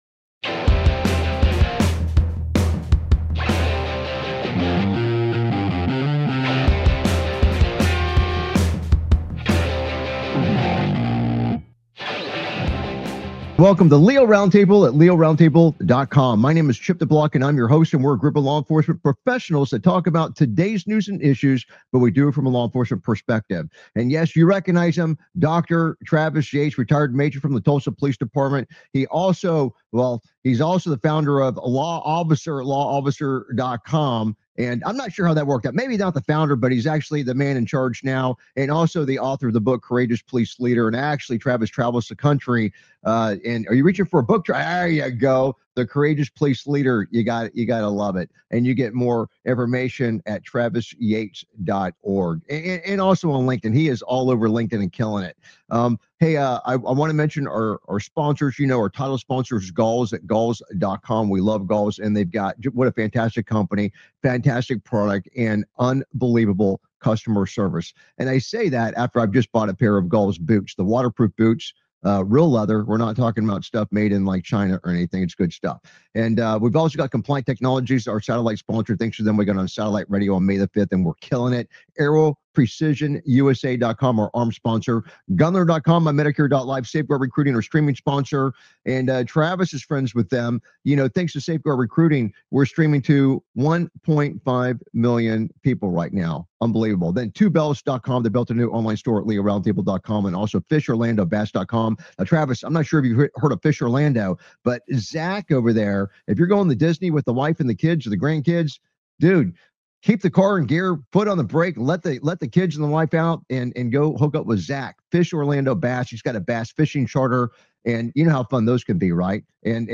Talk Show Episode, Audio Podcast, LEO Round Table and S10E193, Suspect Identified In Shooting That Killed Two At ICE Detention Facility on , show guests , about Suspect Identified In Shooting,Killed Two At ICE Detention Facility, categorized as Entertainment,Military,News,Politics & Government,National,World,Society and Culture,Technology,Theory & Conspiracy